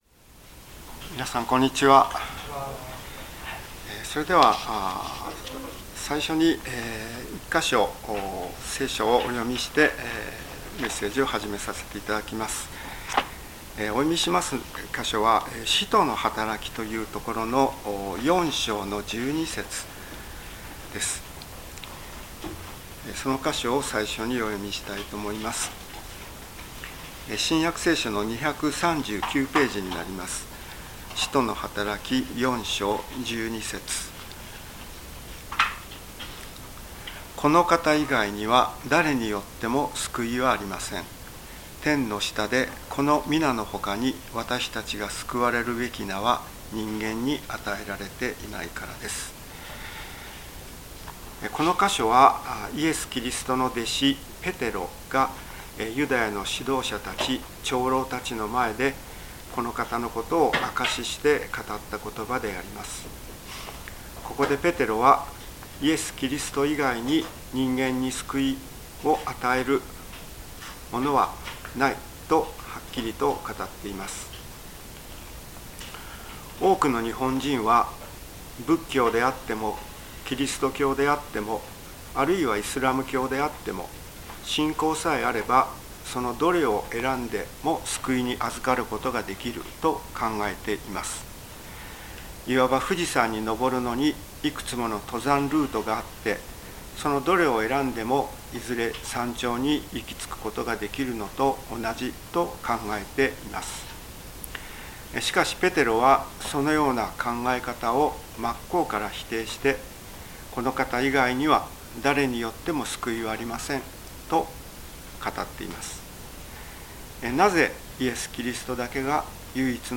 聖書メッセージ No.298